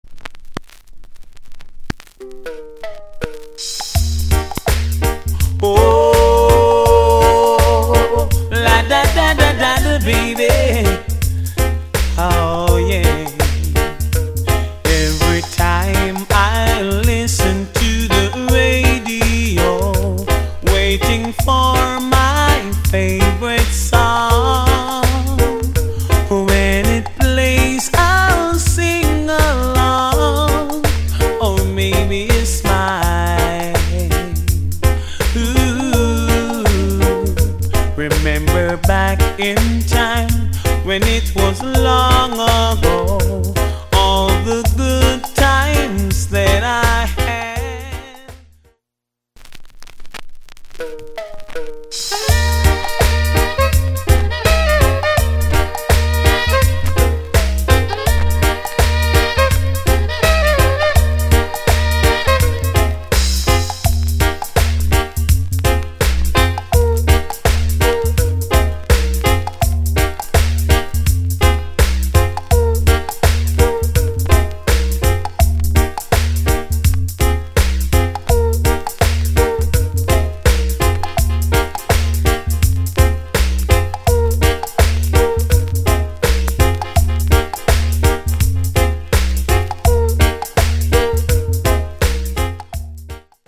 出だし傷によるパチノイズあり。